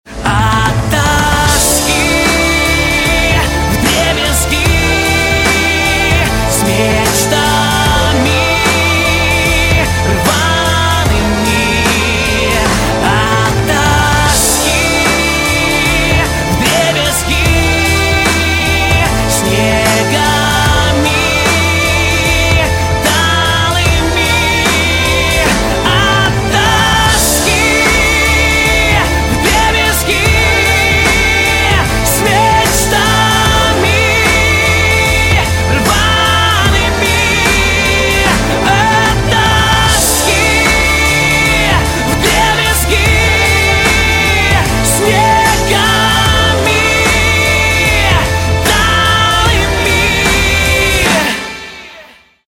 поп
мужской вокал
громкие
грустные
сильные
печальные